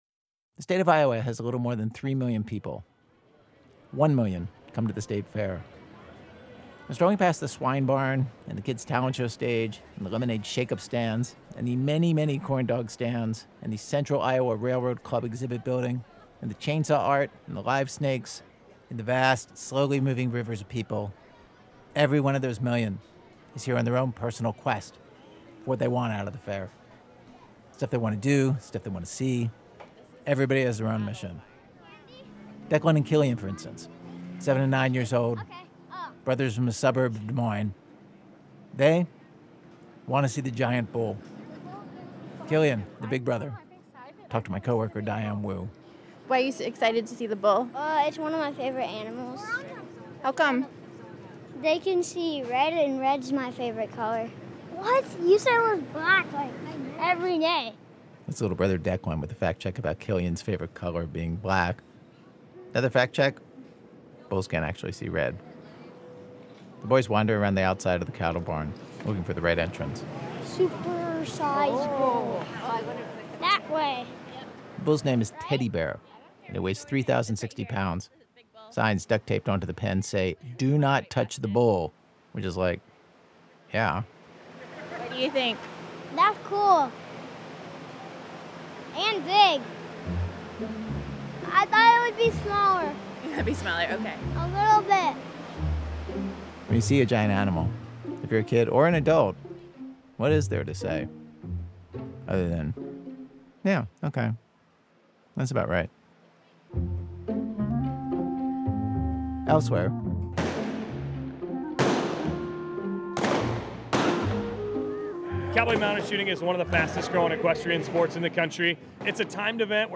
We spend a few days at the Iowa State Fair.
Note: The internet version of this episode contains un-beeped curse words.